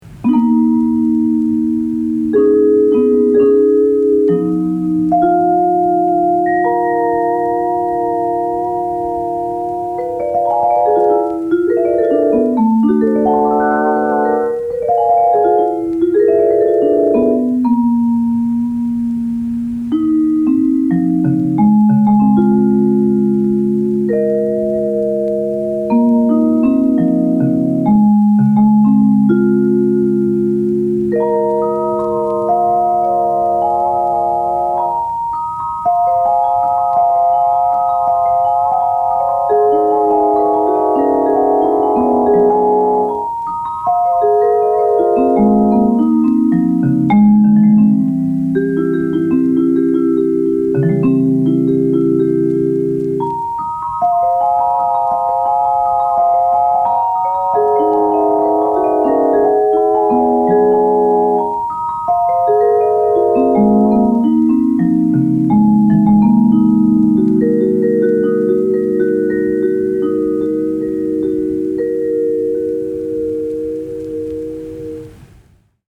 Teletone Audio Vespertone 是一个虚拟乐器插件，它可以让你使用 Wurlitzer, Vibraphone, Celeste 和 Rhodes 四种声学乐器的原始声音，并将它们变形为抽象的版本。